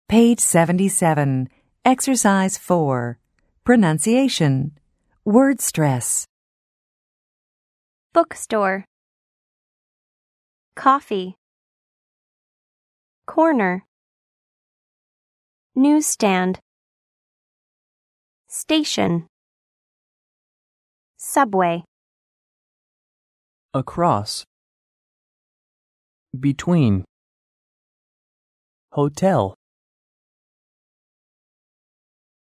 American English